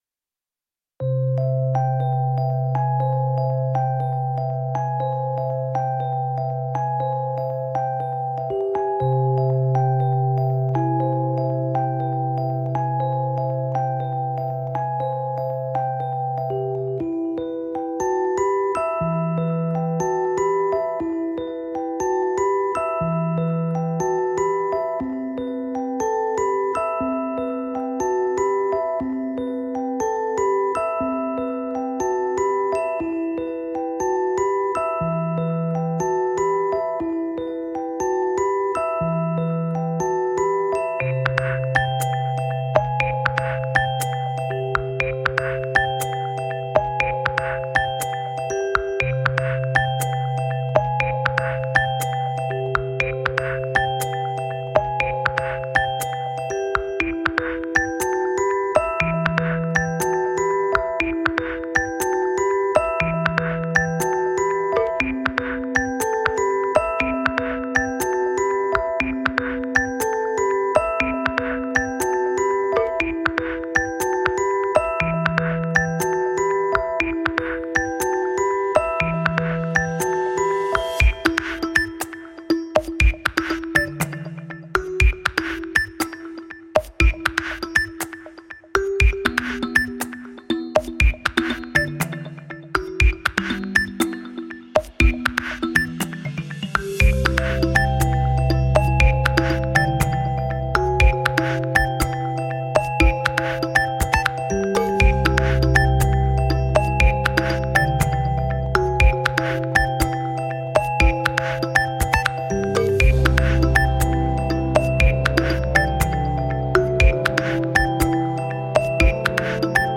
All the songs were composed in a cinematic mood.